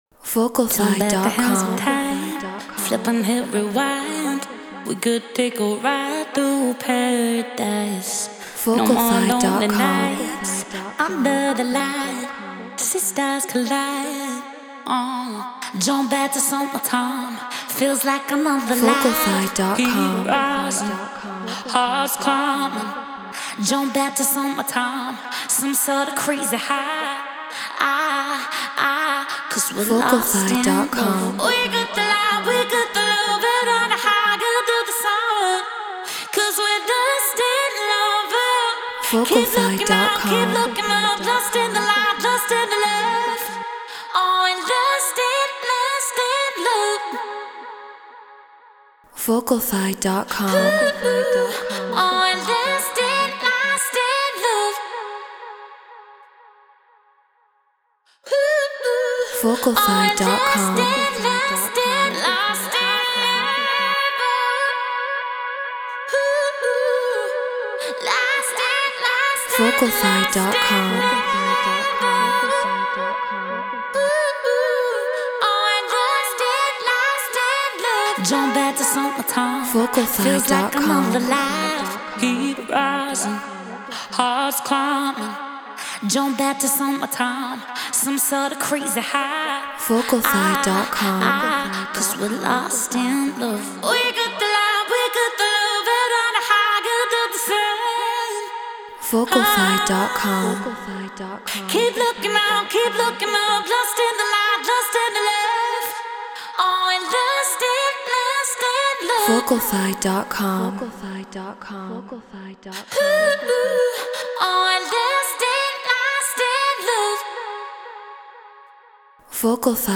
House 123 BPM G#min
Neumann TLM 103 Focusrite Scarlett Pro Tools Treated Room